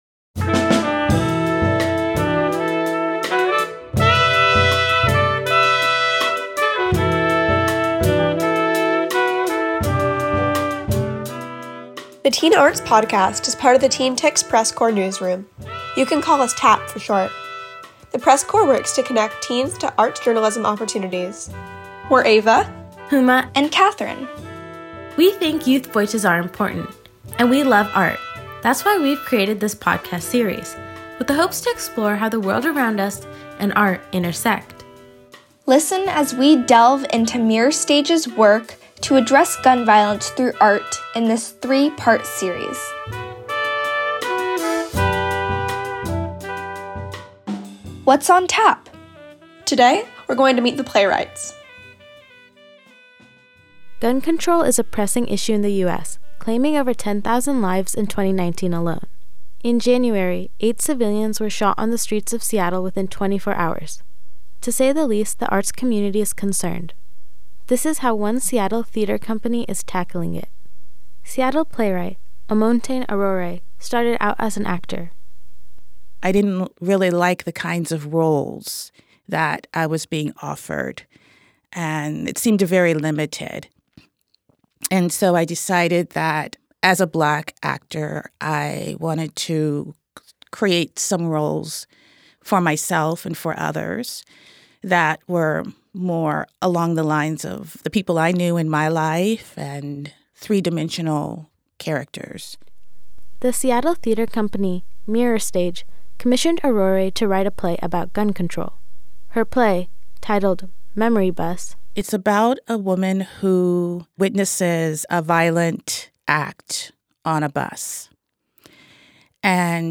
The series is focused on the Mirror Stage production Expand Upon: Gun Control, and the writers, actors and directors share their experiences on the production process and what effect gun violence plays in our society.